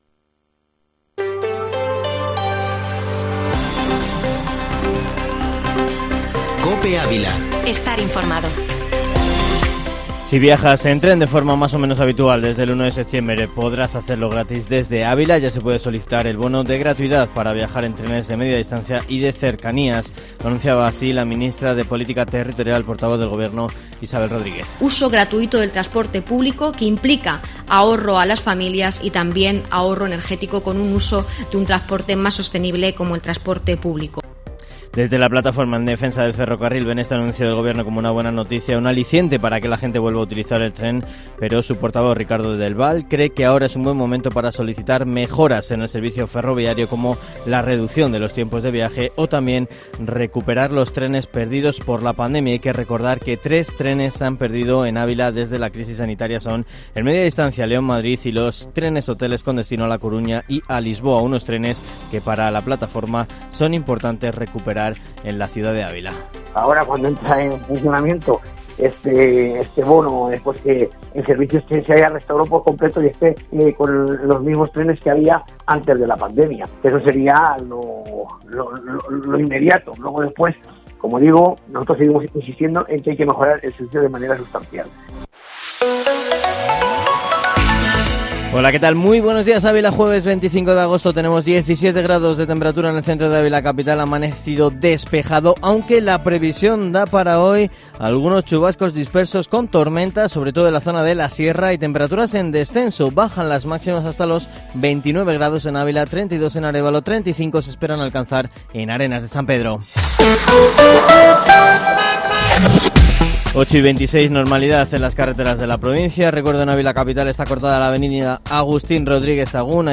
Informativo Matinal Herrera en COPE Ávila -25-agosto